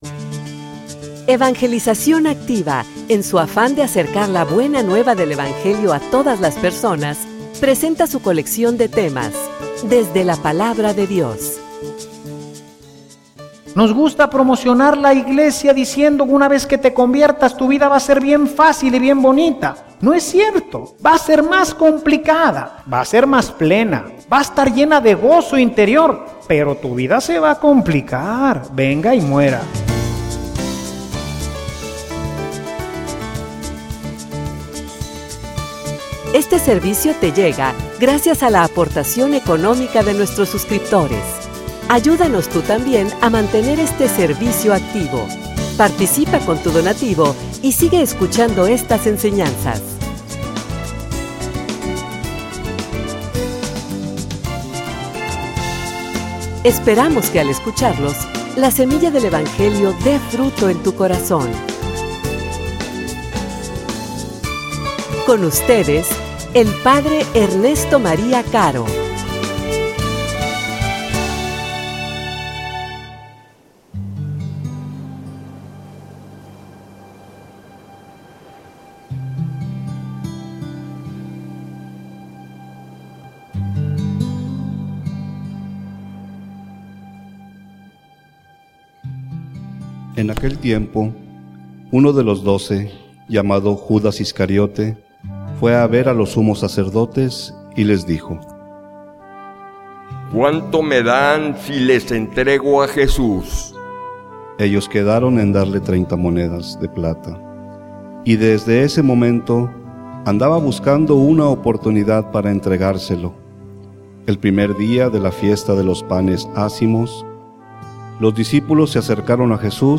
homilia_Venga_y_muera.mp3